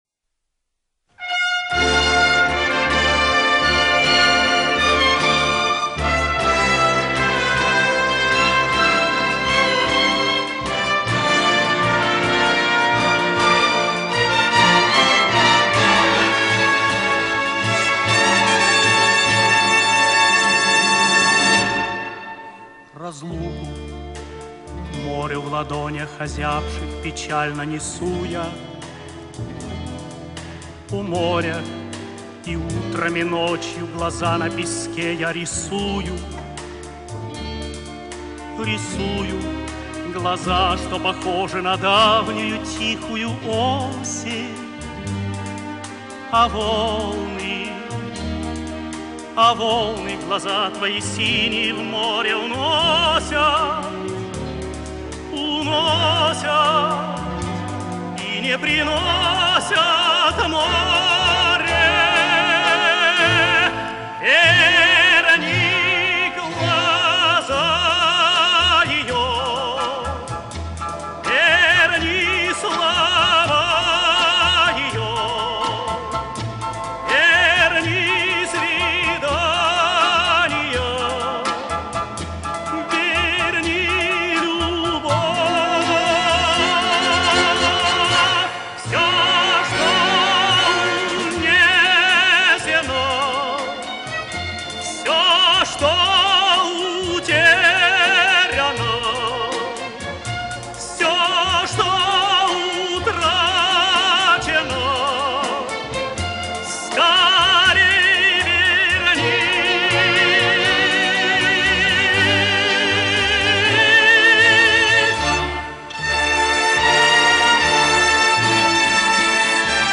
Лирический тенор.